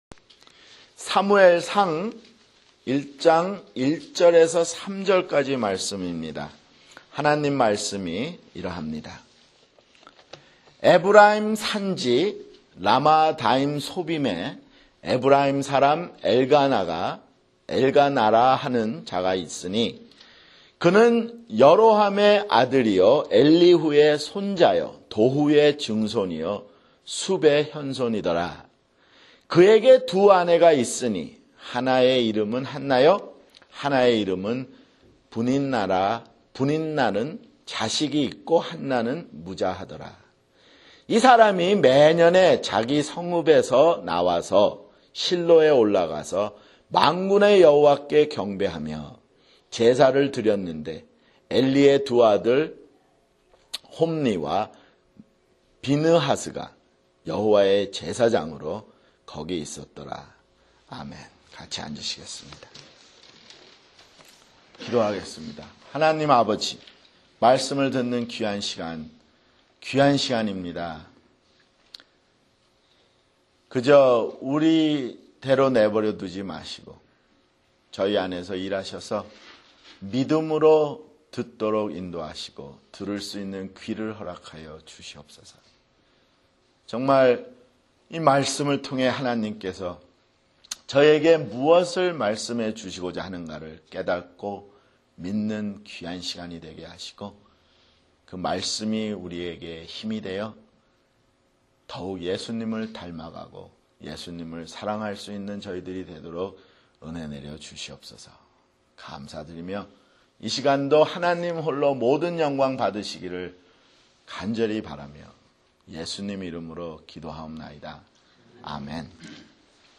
[주일설교] 사무엘상 (3)